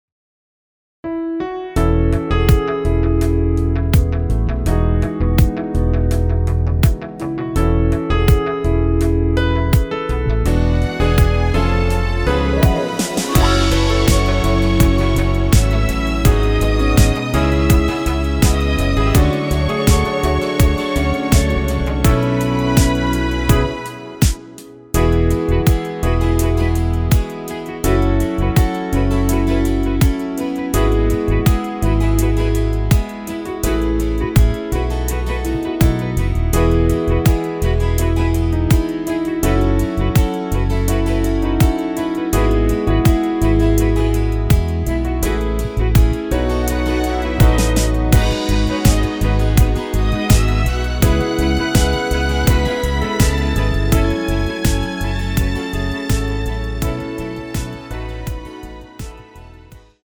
원키에서(+1)올린 MR입니다.
앞부분30초, 뒷부분30초씩 편집해서 올려 드리고 있습니다.